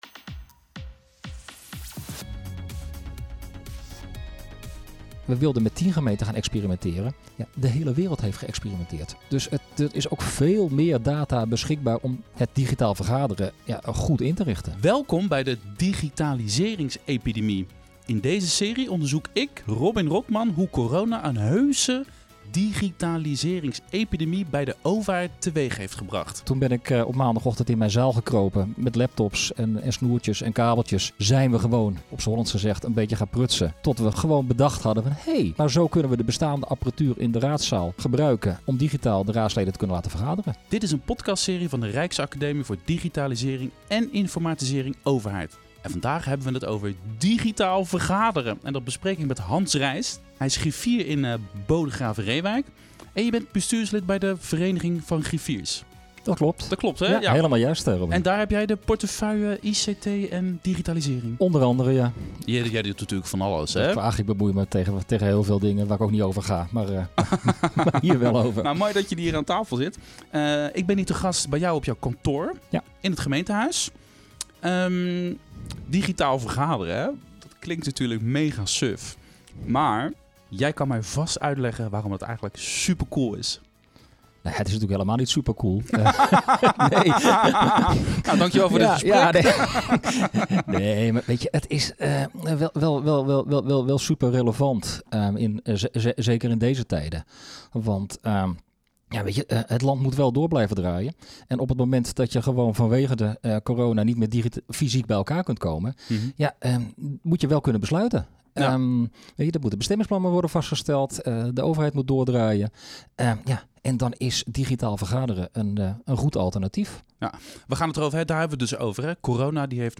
Ik ben hier te gast bij jou, op jouw kantoor, in het gemeentehuis.